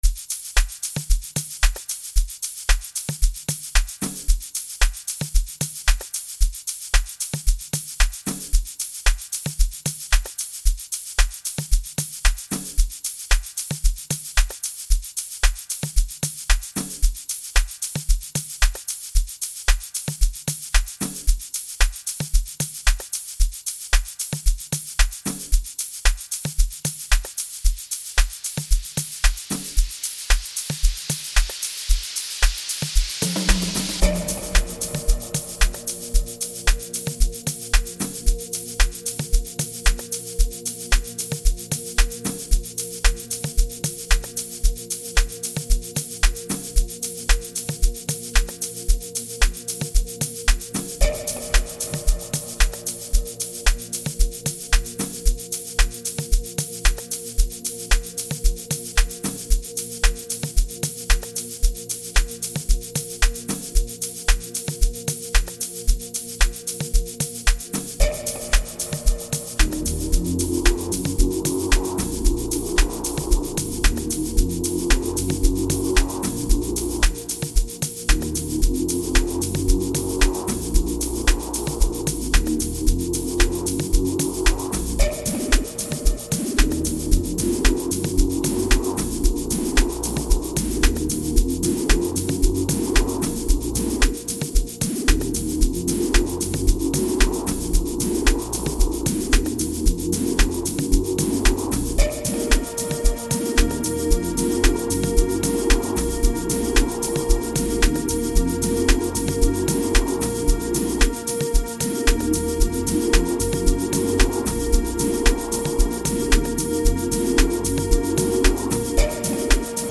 introducing a rare private piano sounds